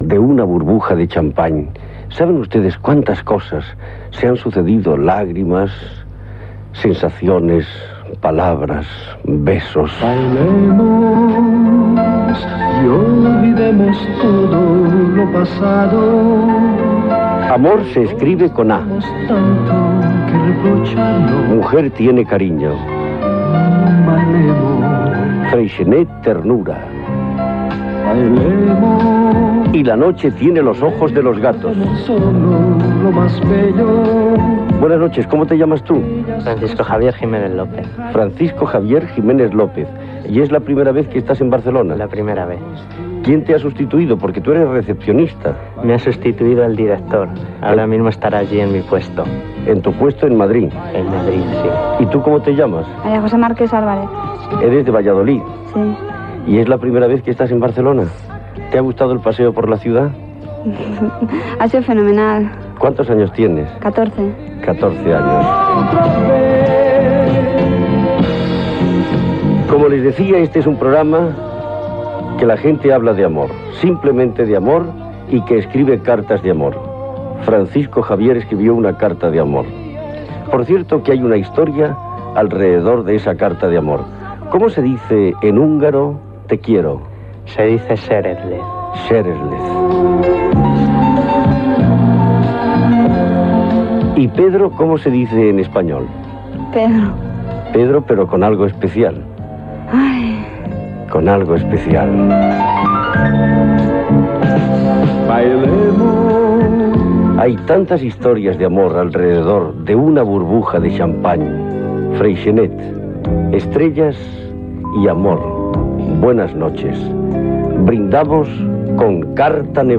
Entrevista a un recepcionista i una estudiant que visiten Barcelona. Comentaris sobre l'amor, les "flors humanes"...
Entreteniment